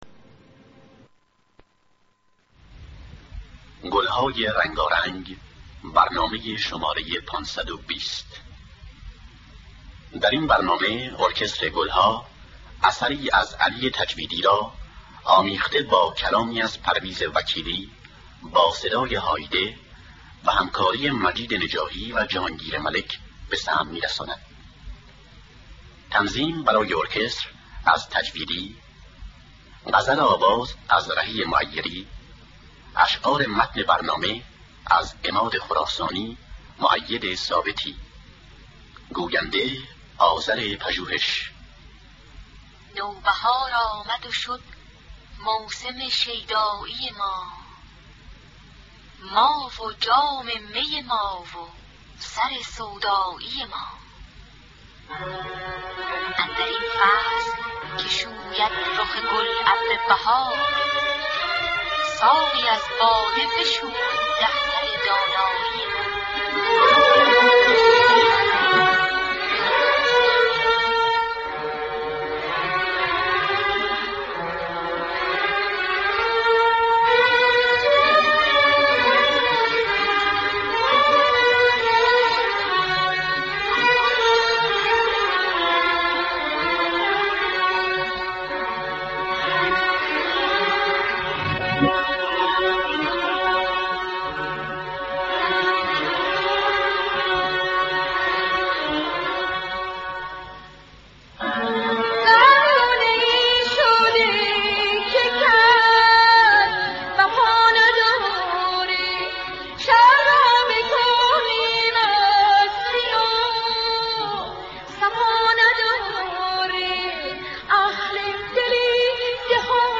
دستگاه سه‌گاه